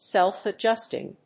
アクセントsélf‐adjústing